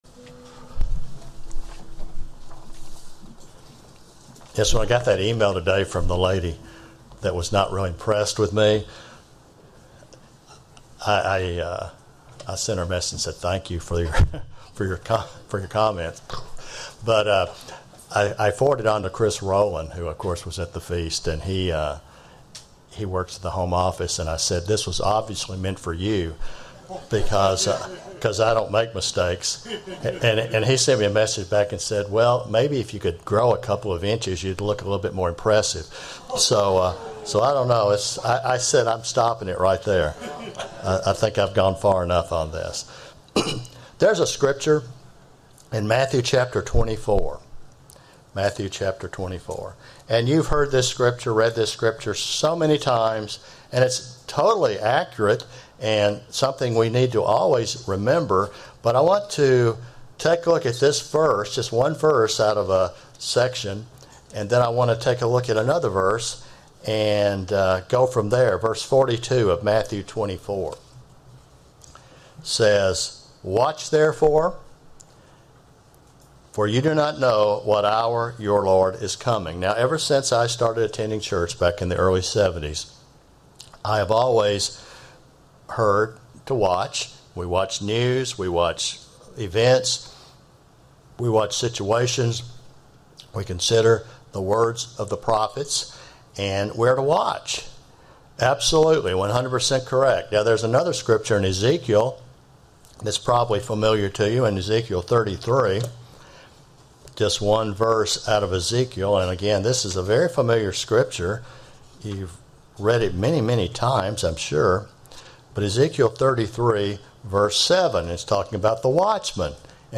Sermon
Given in Tampa, FL